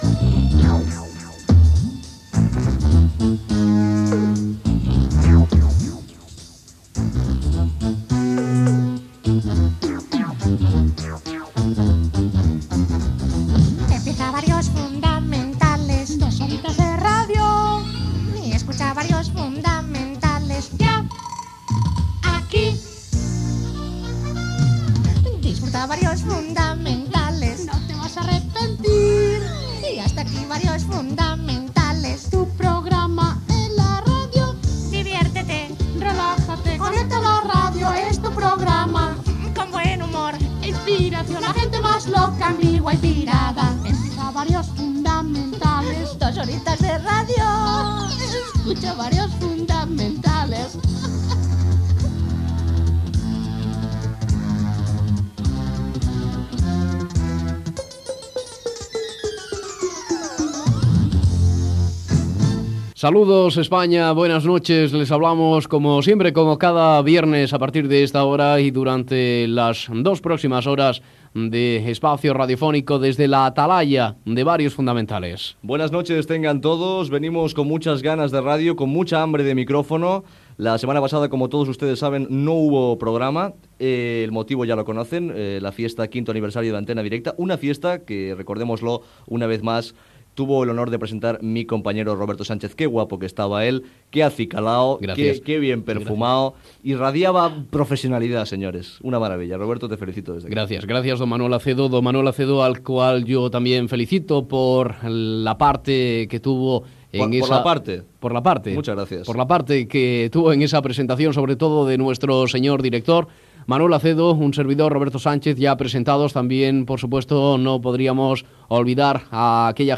Careta cantada del programa, presentació, telèfons i primer tema musical.
Entreteniment
FM